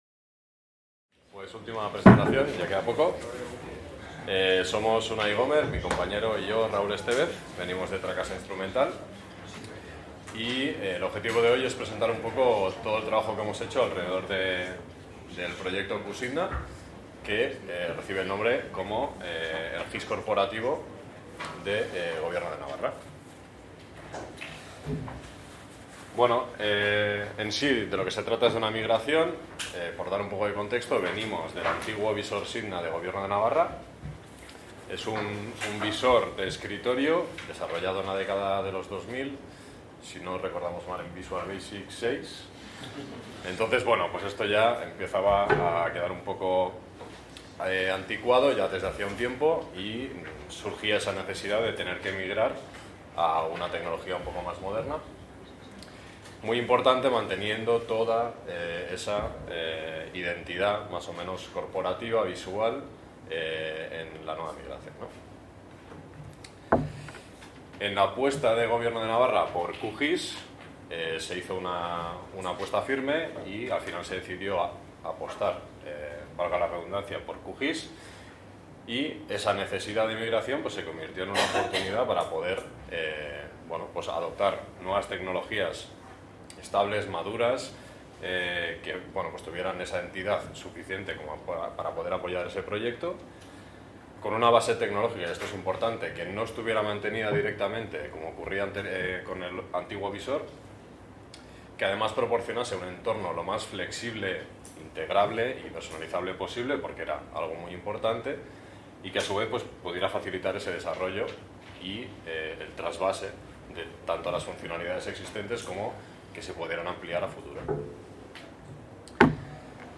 En el marc de les 18enes jornades SIG Libre, Geotech & Spatial Data Science